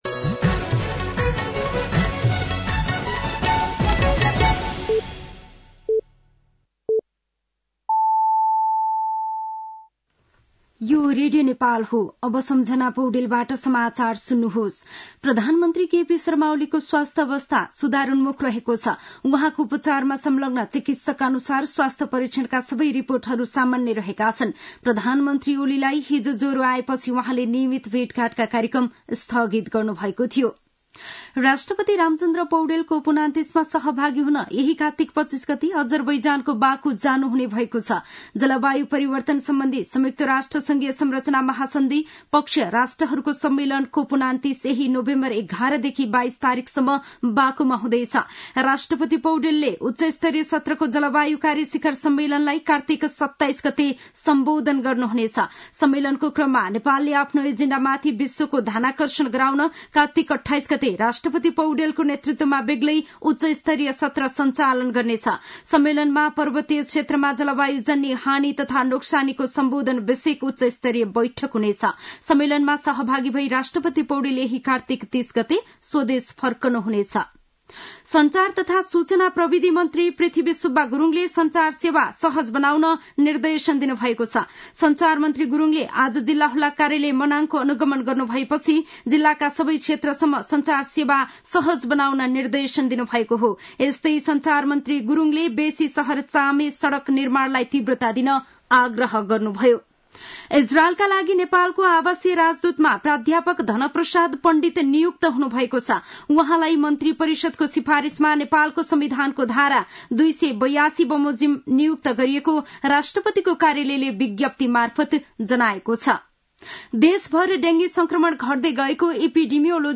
साँझ ५ बजेको नेपाली समाचार : २२ कार्तिक , २०८१
5-pm-news.mp3